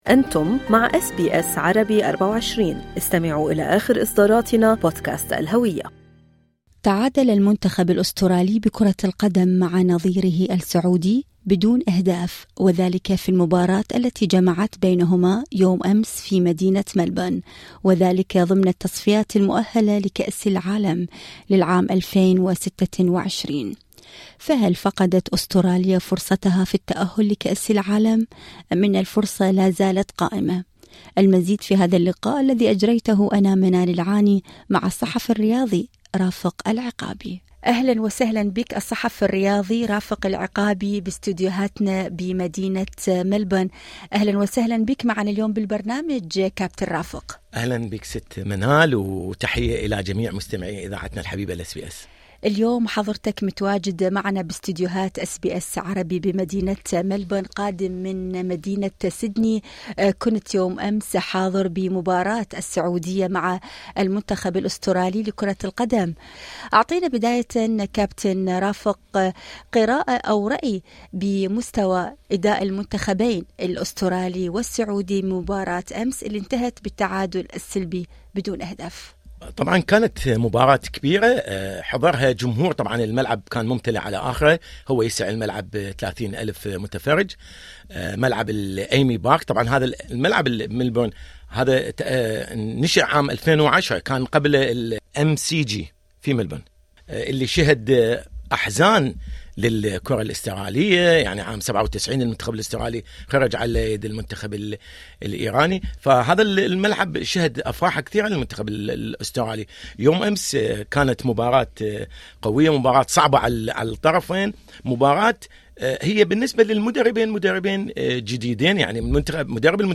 الذي حضر اليوم إلى استوديوهات أس بي اس عربي في ملبورن